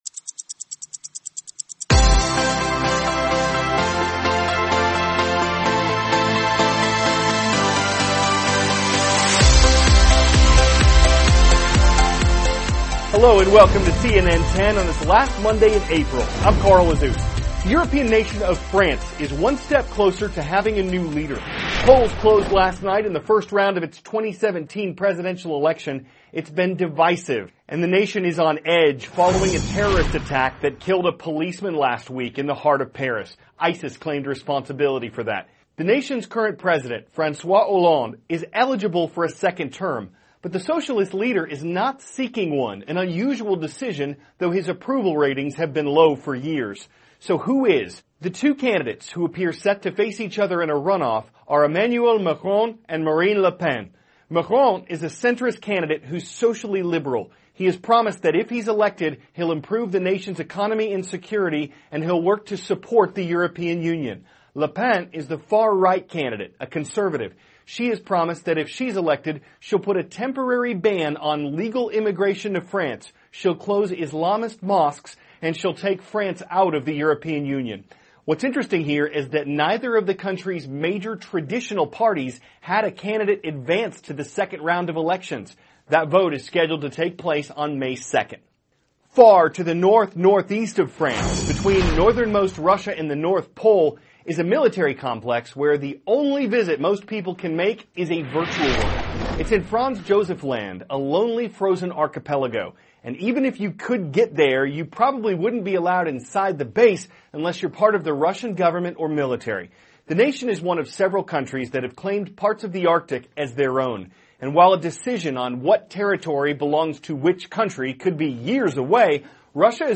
*** CARL AZUZ, cnn 10 ANCHOR: Hello and welcome to cnn 10 on this last Monday of April.